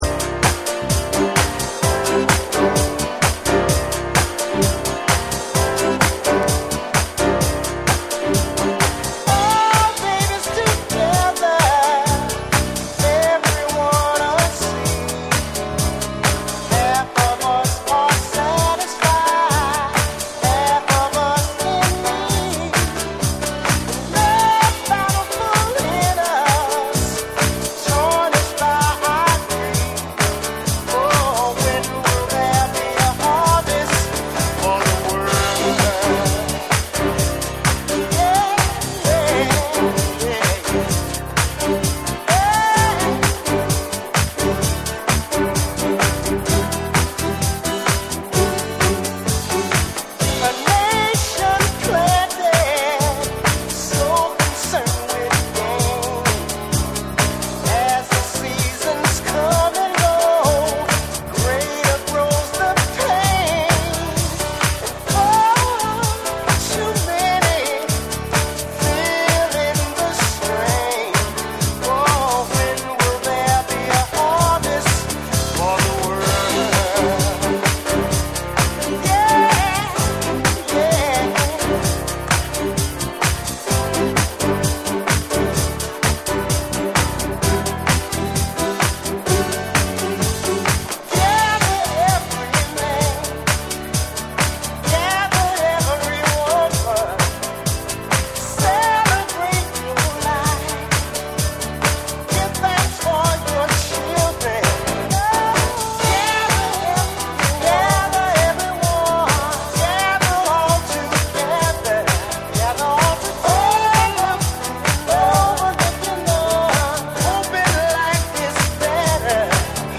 TECHNO & HOUSE / SOUL & FUNK & JAZZ & etc